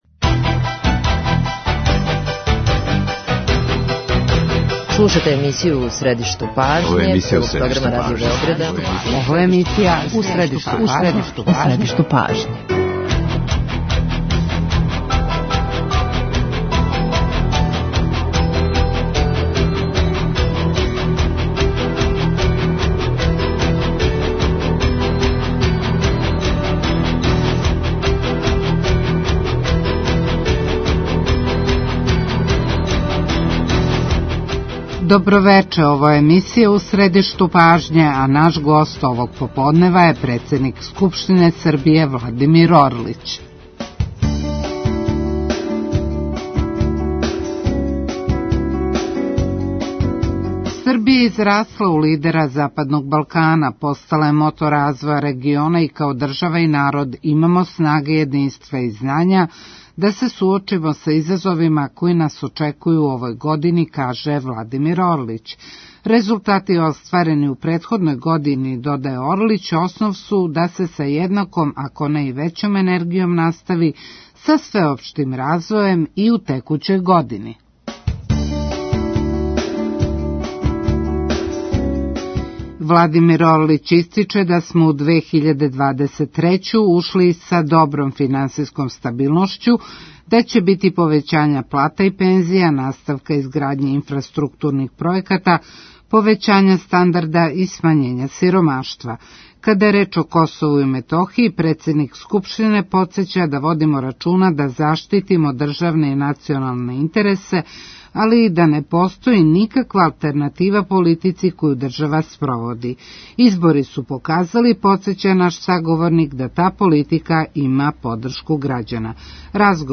Гост емисије У средишту пажње данас је председник Скупштине Србије Владимир Орлић. Са њим разговарамо о изазовима и искушењима са којима ћемо се као држава и грађани суочити у текућој години, позицији и ставовима Србије око кључних националних и државних питања, односно подршци коју за њих добијамо из дела међународне заједнице.